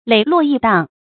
磊落轶荡 lěi luò yì dàng
磊落轶荡发音